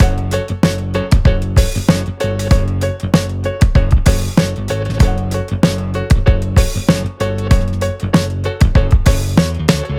B:リズムだけがんばった音楽
一方Bは、和音を分析しても面白いものは何ひとつ出てこなくて、ただ同じ音を弾き続けているだけの単調な曲です。でも単純に音楽としての聴き心地がよくて、ココにラップなんか乗せたらちゃんとカッコいい楽曲に仕上がるでしょう。